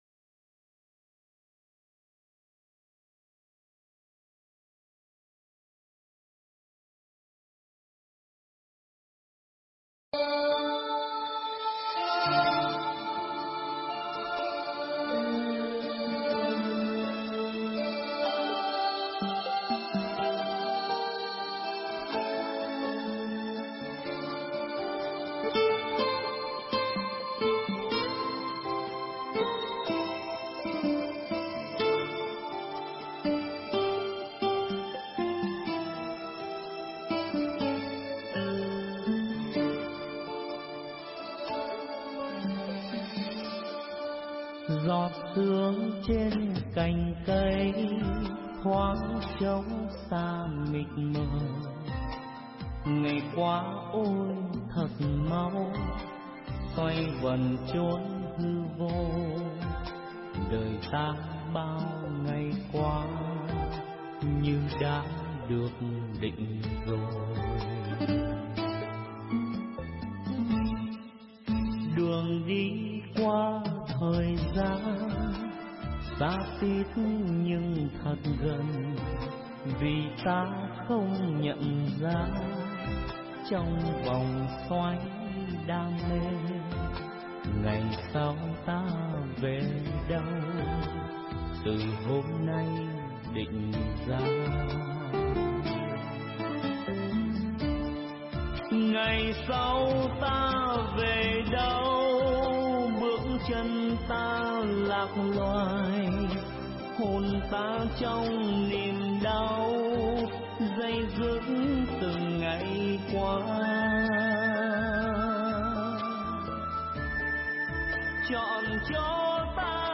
thuyết giảng tại Chùa Hải Đức, Canada